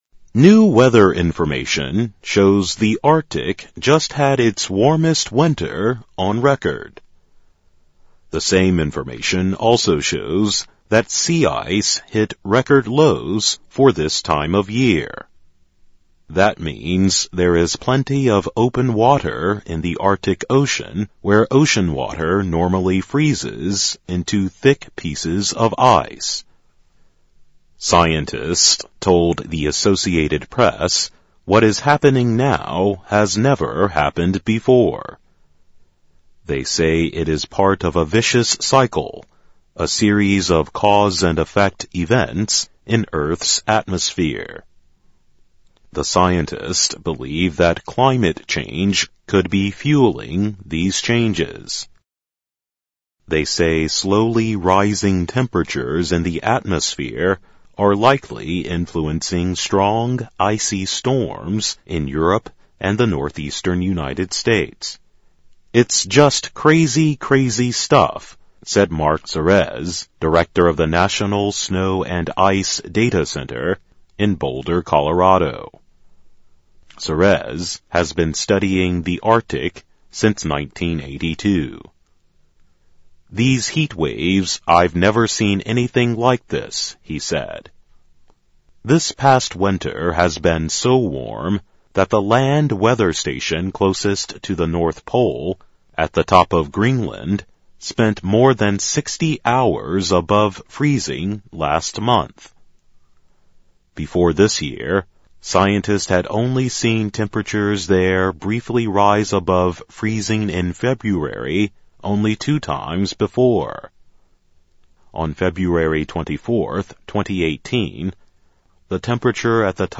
在线英语听力室科学家称北极的暖冬气温已破纪录的听力文件下载,2018年慢速英语(三)月-在线英语听力室